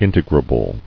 [in·te·gra·ble]